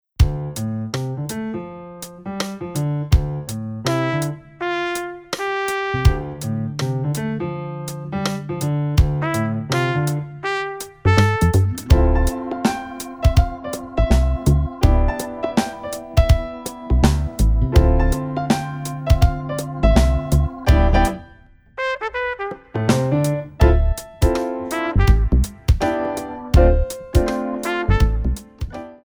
Hip Hop / Jazz
4 bar intro
moderato